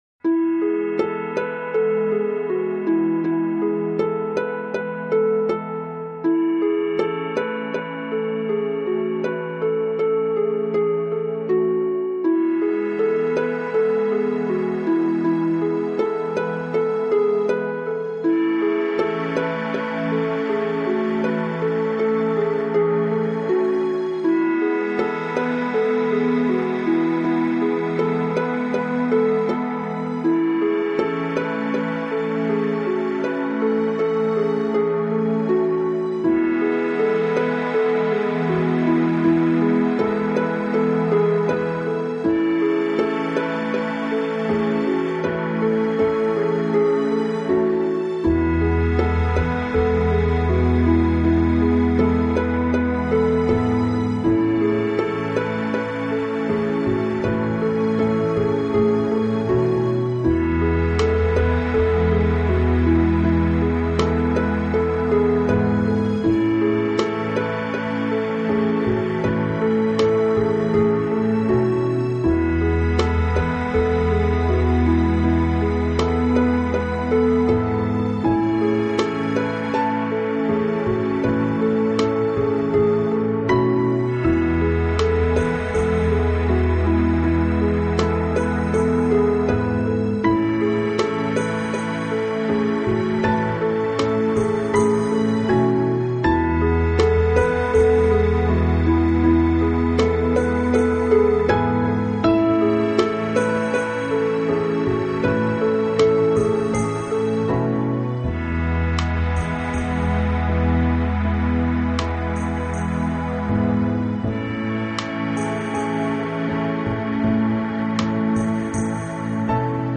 Genre: New Age, Relax, Instrumental
This certainly is soothing music.